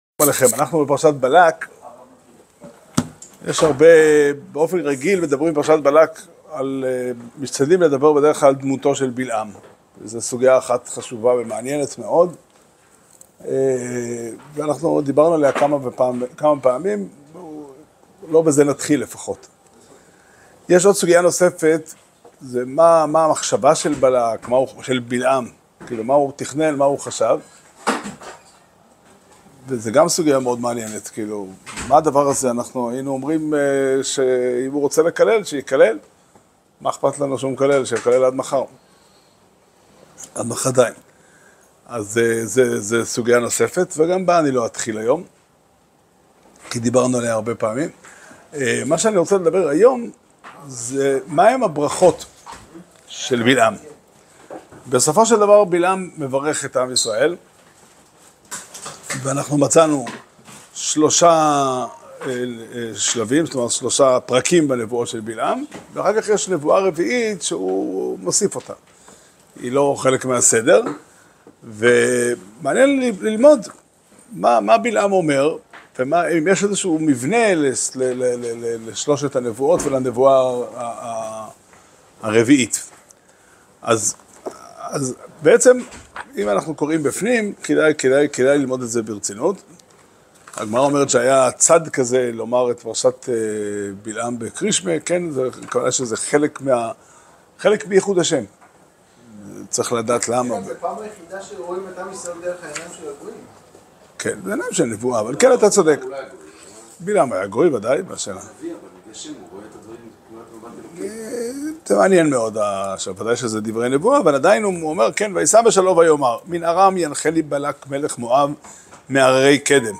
שיעור שנמסר בבית המדרש פתחי עולם בתאריך ח' תמוז תשפ"ד